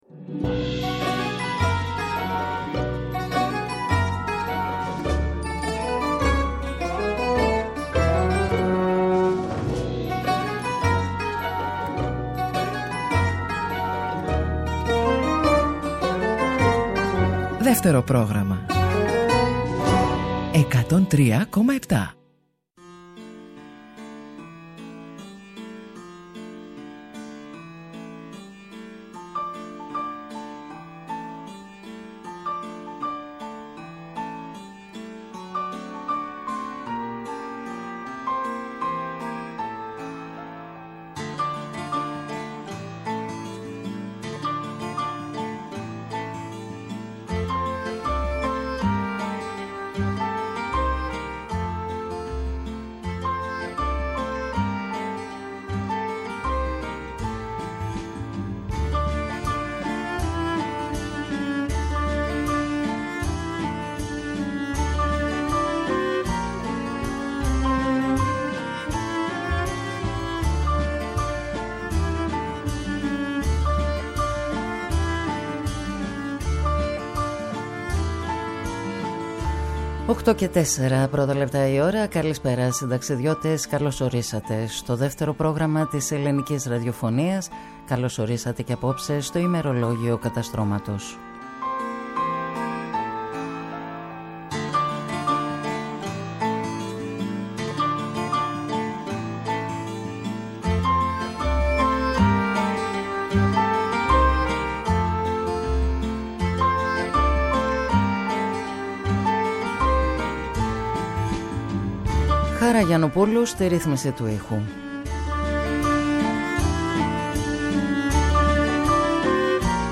Συνταξιδιώτες οι ακροατές, ούριος άνεμος η μουσική και τα τραγούδια.